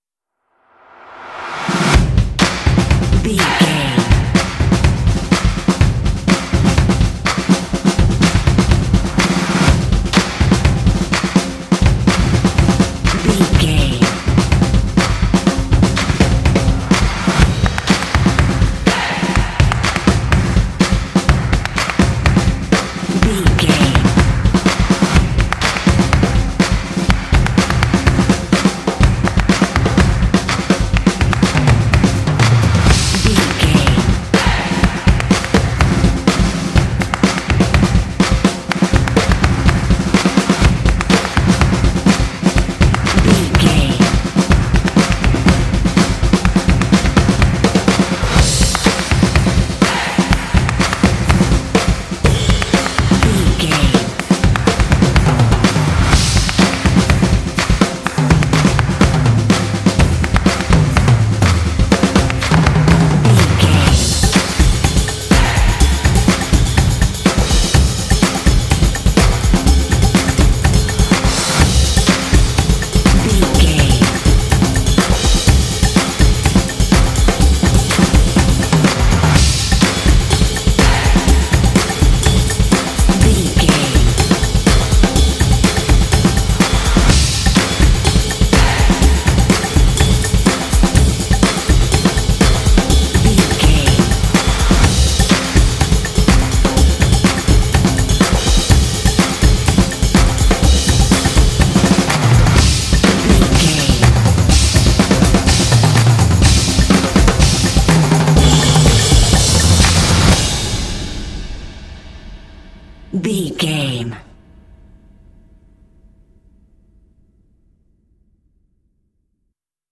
This epic drumline will pump you up for some intense action.
Epic / Action
Atonal
driving
motivational
drums
percussion
vocals
drumline